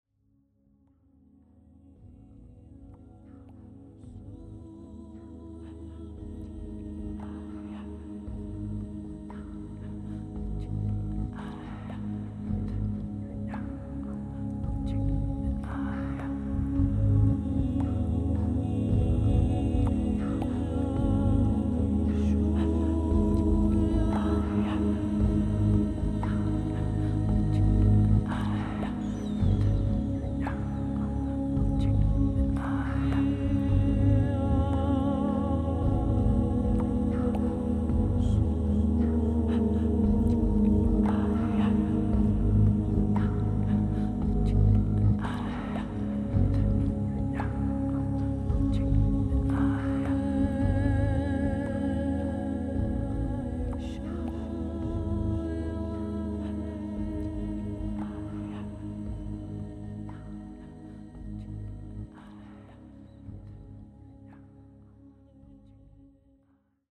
Music For Meditation